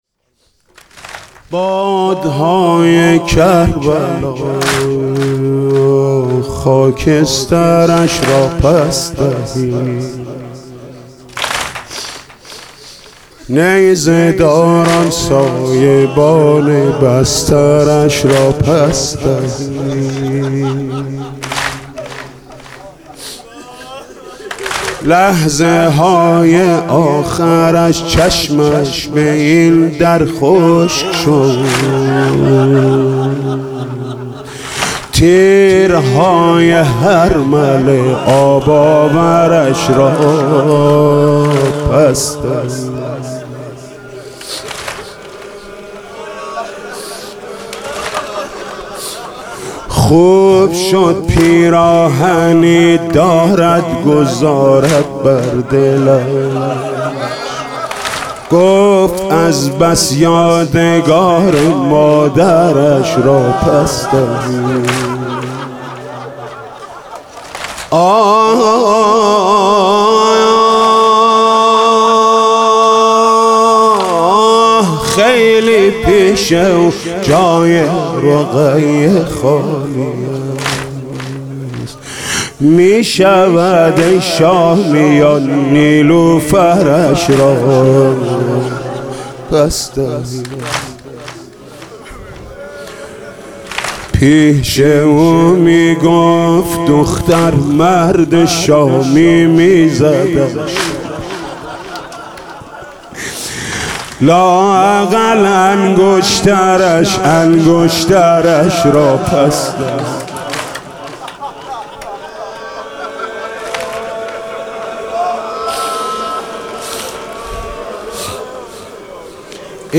واحد: باد های کربلا خاکسترش را پس دهید